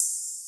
Open Hat (Outside)(1).wav